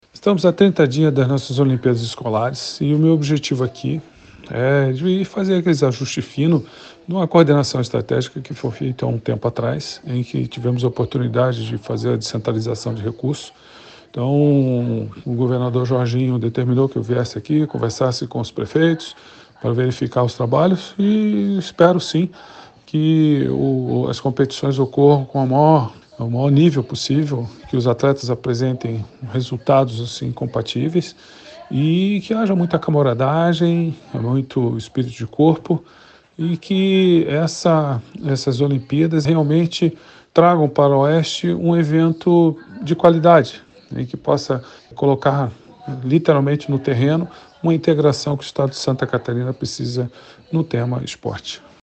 O presidente comentou que o propósito do encontro foi analisar como está a questão da organização do evento em Maravilha, o contato com os outros municípios e mostrar que a Fesporte está atuando junto nesta organização:
SECOM-Sonora-presidente-da-Fesporte.mp3